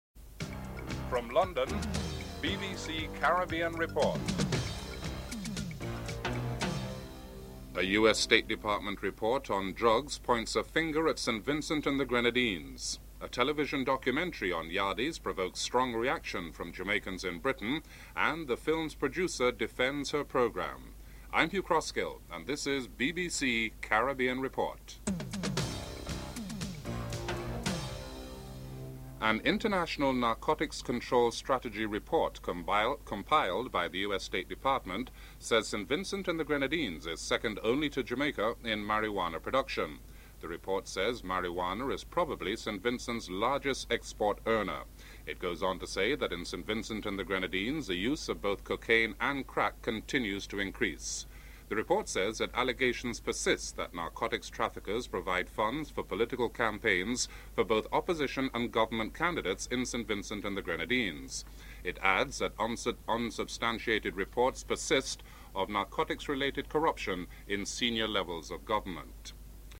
An International Narcotics Control Strategy Report compiled by the United States State Department indicated that St. Vincent and the Grenadines is second only to Jamaica in marijuana production. A British television documentary entitled "The Yardies" has provoked a strong reaction from the Jamaican community in the United Kingdom. Caribbean Report plays some highlights from this controversial British television programme.